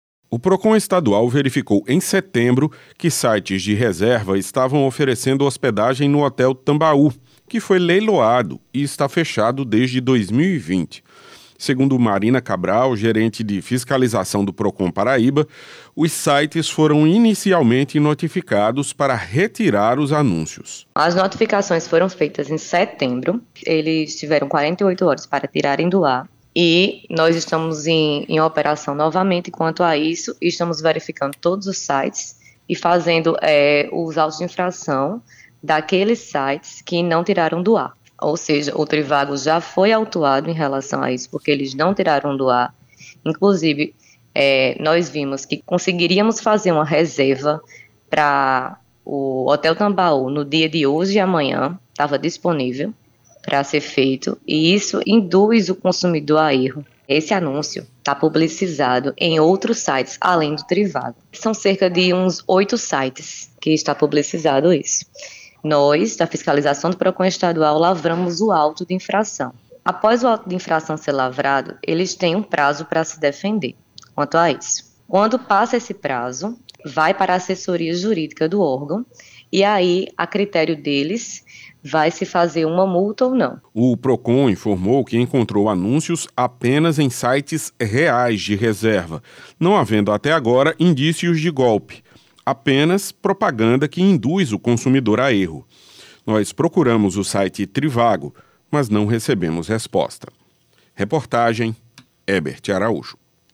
Reportagem: plataforma continua anunciando hotel Tambaú como opção de hospedagem na capital